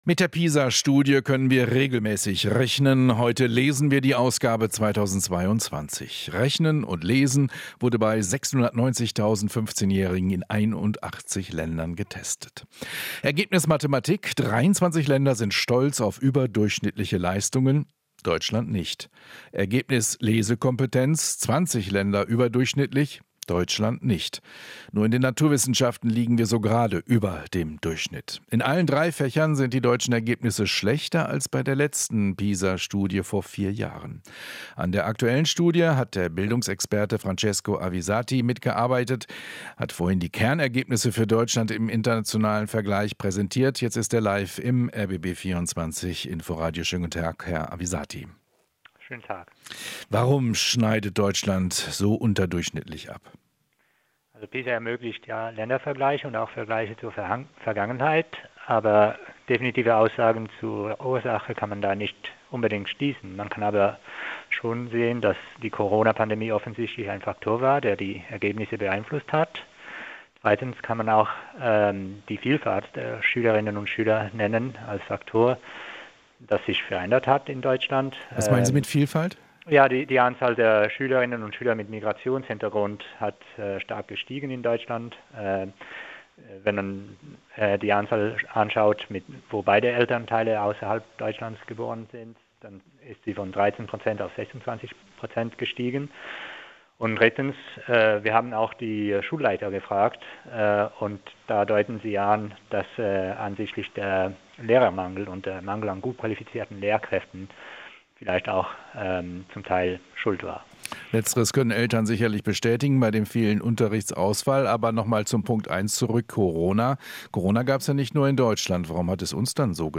Interview - Neue Pisa-Studie: Deutsche Schüler so schlecht wie nie zuvor